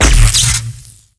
pokroe_explo.wav